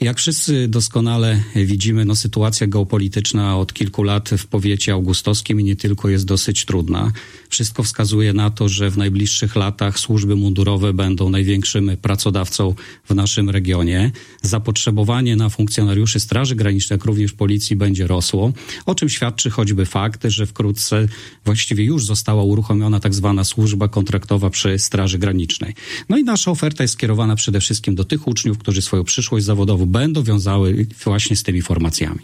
O szczegółach mówił w środę (16.04.25) w Magazynie Powiatu Augustowskiego w Radiu 5 Wojciech Jerzy Szczudło, wicestarosta augustowski. Jak wyjaśnił, taki oddział to odpowiedź na zapotrzebowanie służb.